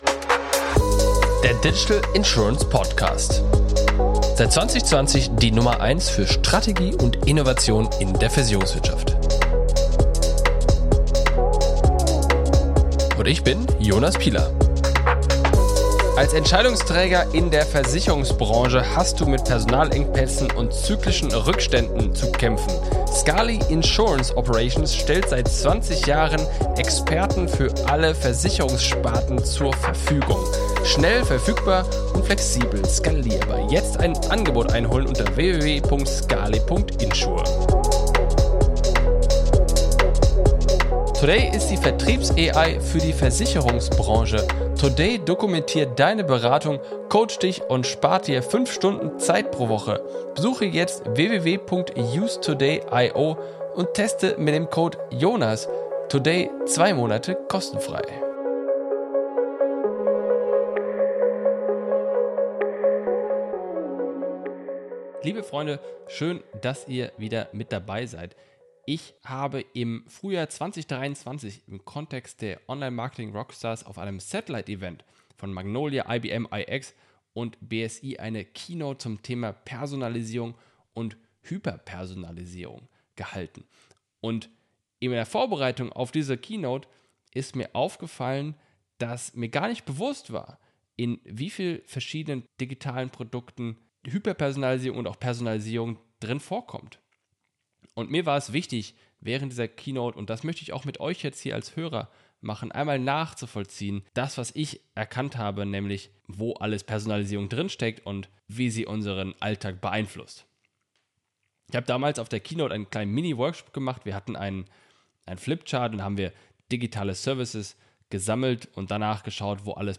Mit diesen Fragen bechäftigten sich Magnolia, BSI, IBM iX gemeinsam mit den verschiedensten Gästen aus der Versicherungsbranche am 8. Mai in Hamburg und ich war als Keynote Speaker vor Ort.